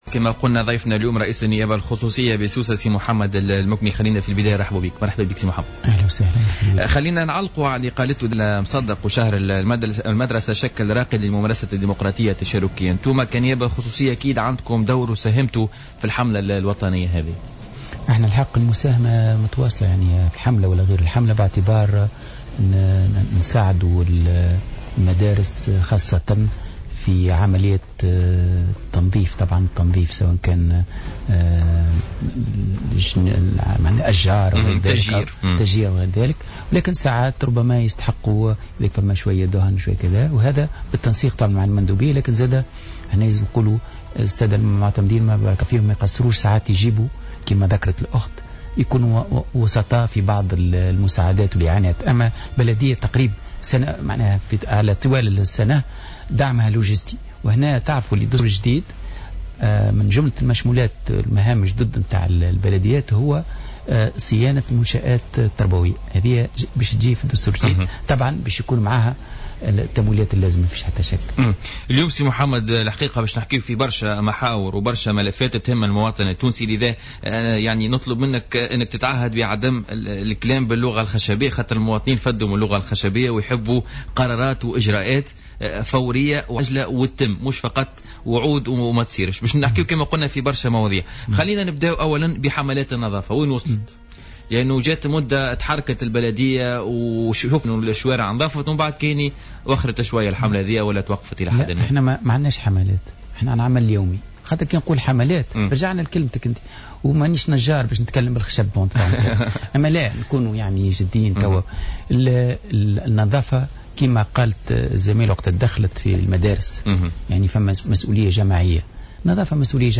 أعلن رئيس النيابة الخصوصية بسوسة محمد المكني اليوم الثلاثاء على "الجوهرة أف أم" في برنامج "بوليتيكا" أنه سيتم إعادة توزيع محطات النقل وتنظيمها للحد من الضغط المروري وسط مدينة سوسة.